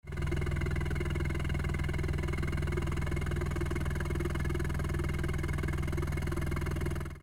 GTbox06&S専用サイレンサー（アイドリング）
ビビり音がほとんど聞こえないのが分かりますし
copen_la400-kakimoto_genuine_baffle_idling.mp3